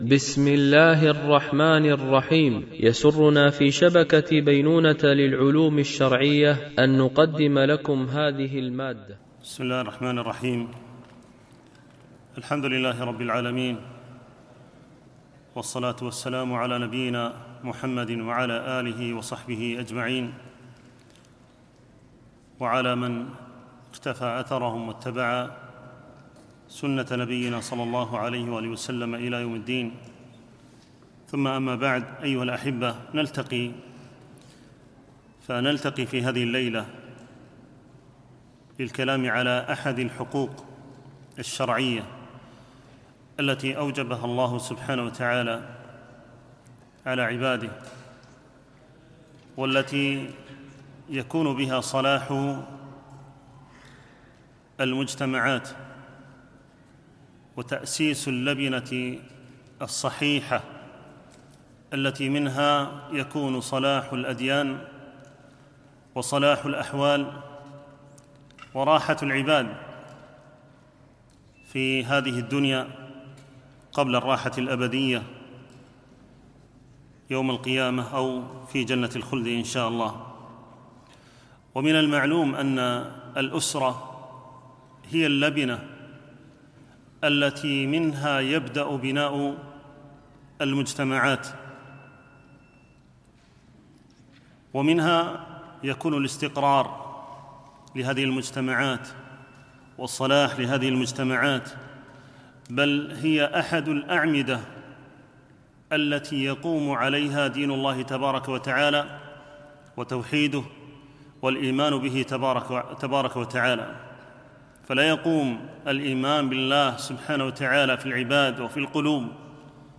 دورة علمية بعنوان: حقوق أسرية، بمسجد أم المؤمنين عائشة - دبي (القوز ٤)